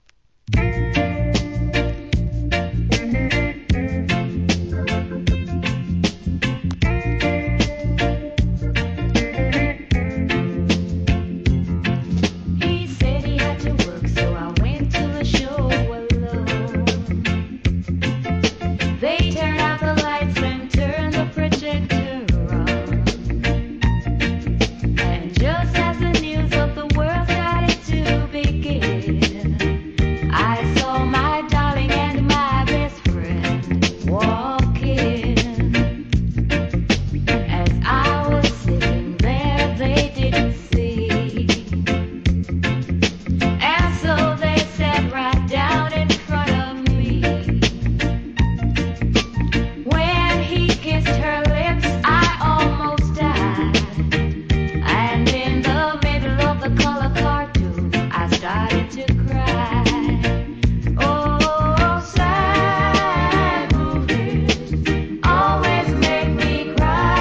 LOVERSファンにオススメのNICEヴォーカル物揃ってます。